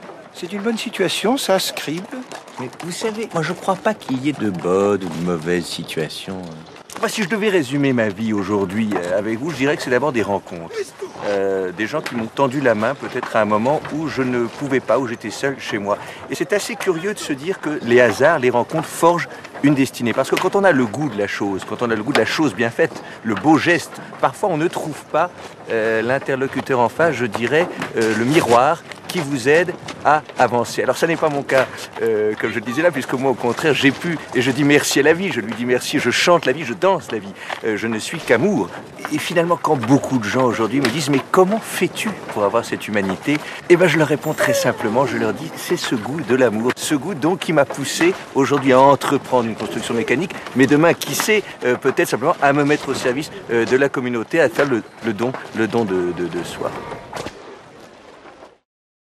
Oui, il s'agit bien du monologue d'OTIS avec le très décalé EDOUARD BEAR.